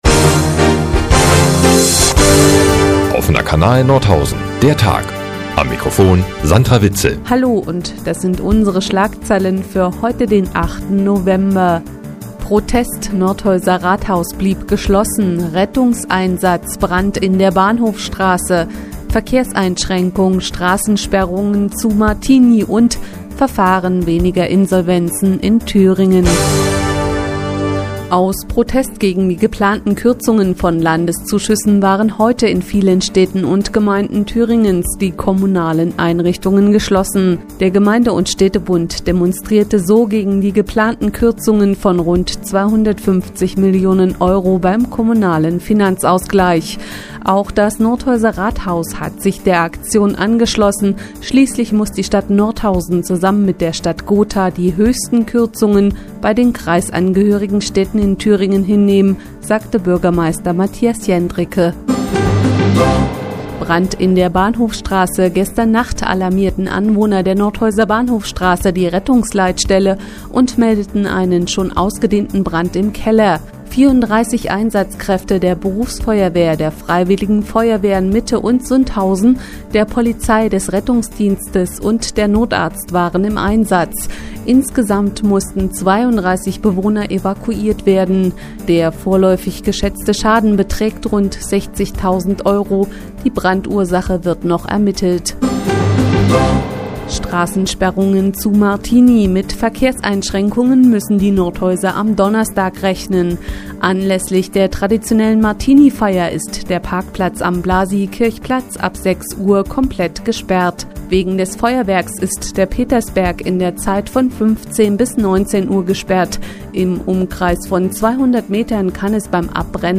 Die tägliche Nachrichtensendung des OKN ist nun auch in der nnz zu hören.